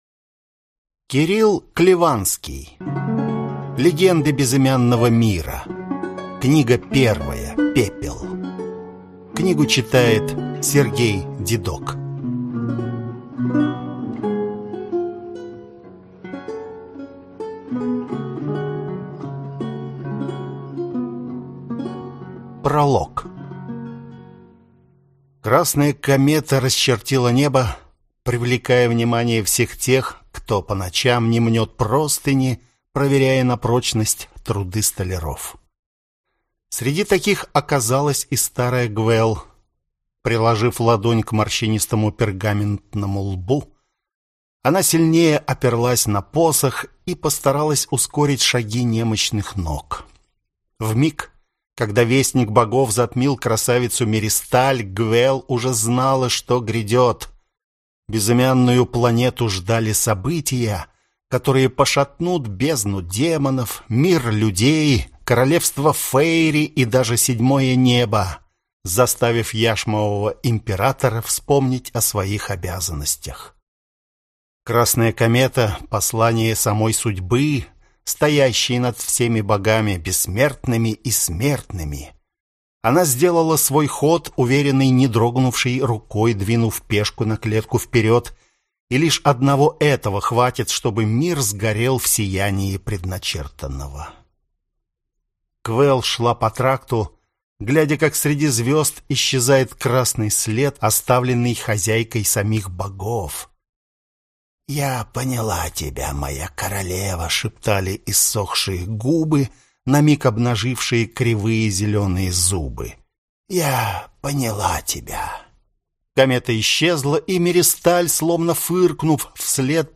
Аудиокнига Легенды Безымянного Мира. Книга 1. Пепел | Библиотека аудиокниг